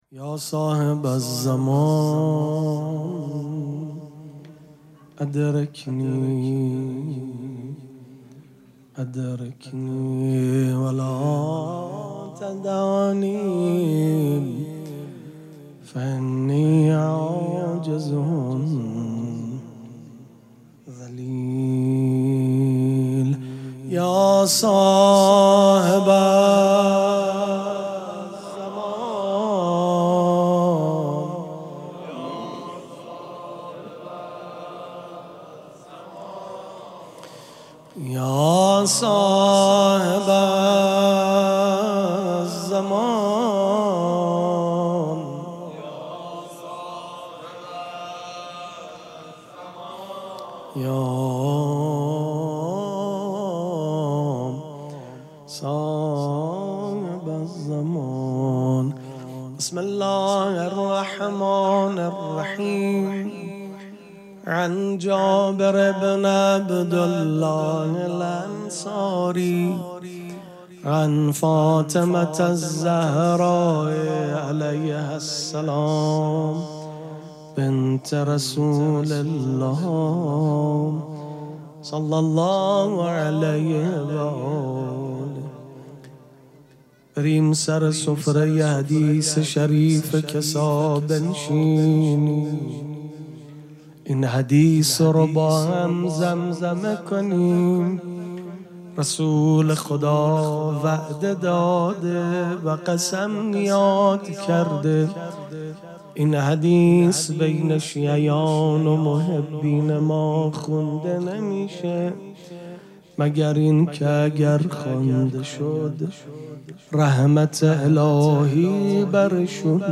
مراسم جشن ولادت حضرت زهرا سلام الله علیها
سبک اثــر پیش منبر